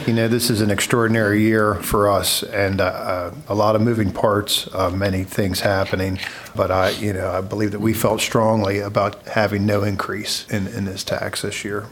During last night’s Allegany County Commissioners meeting, an outline of the FY 2026 budget was presented which included the $0.02 property tax increase.  However, the Commissioners voted against increasing property tax altogether.
Commissioner Dave Caporale and the other commissioners said they don't want to increase the tax this year…